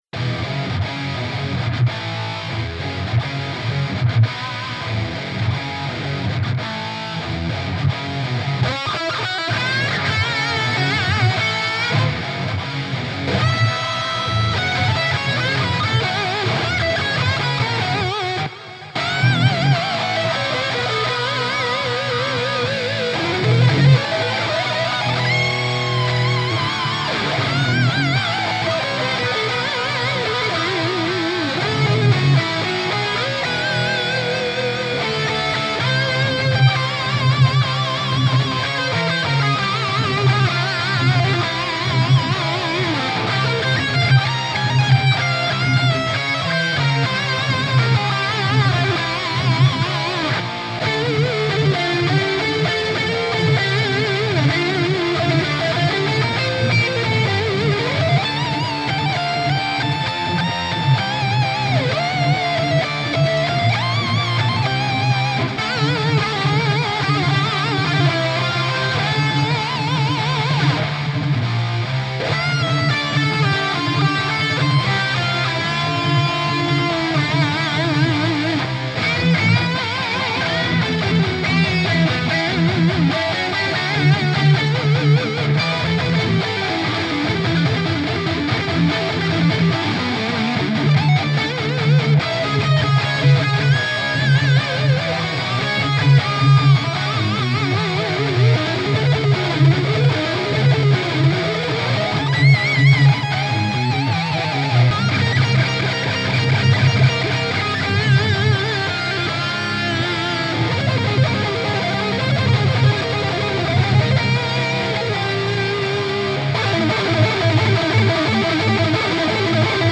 A collection of rock metal guitar tracks ranging from 102 to 185 BPM, perfect for adding a little raw grunt to your tracks.